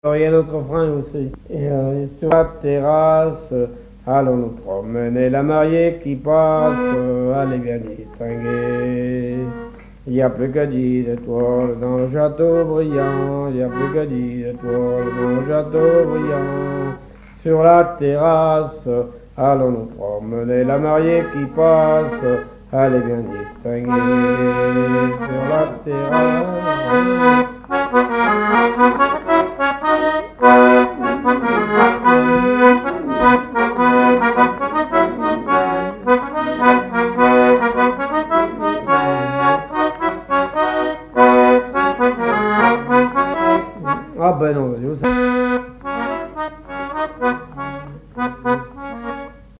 fiançaille, noce
Genre énumérative
accordéon diatonique
Pièce musicale inédite